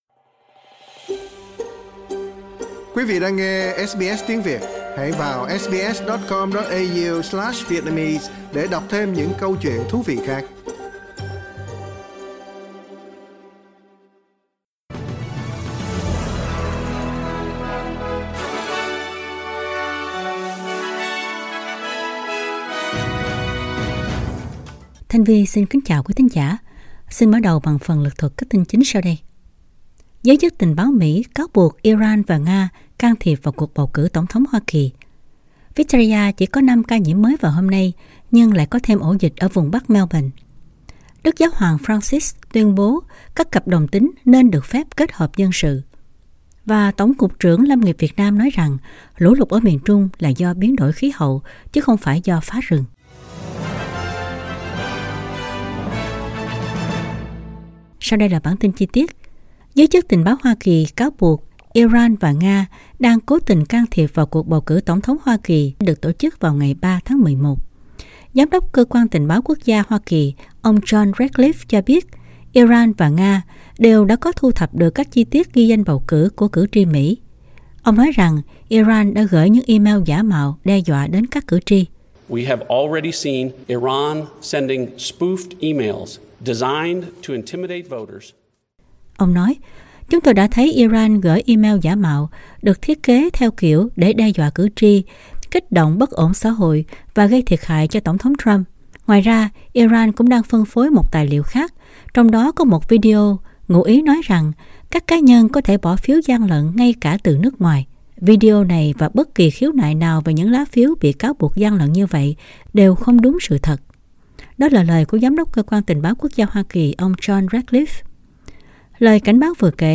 Bản tin chính trong ngày của SBS Radio.